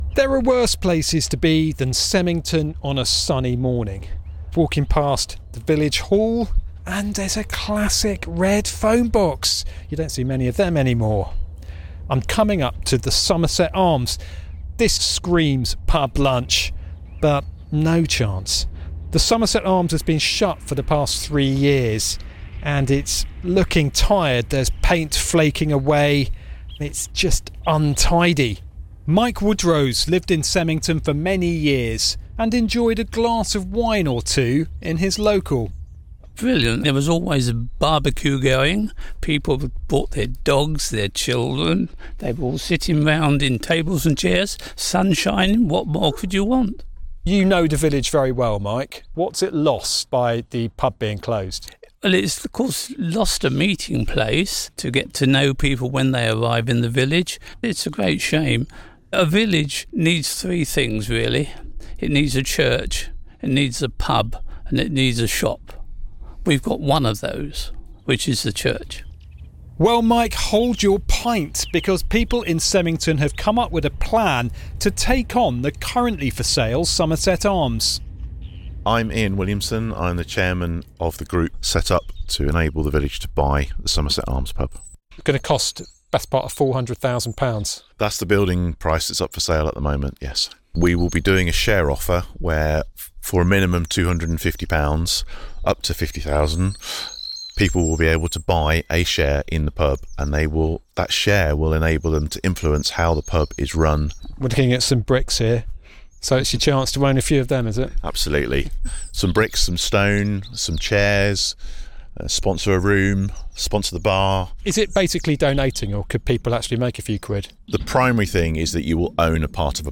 BBC Radio Wiltshire broadcast